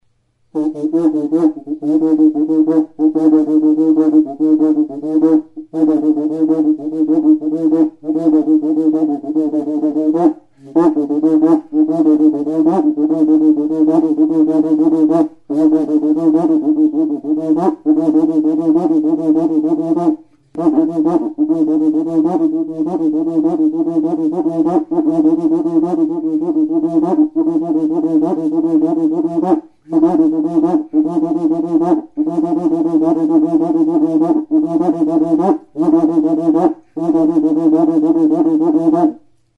Membranophones -> Frictioned / rubbed -> Stick
Recorded with this music instrument.
SIMBOMBA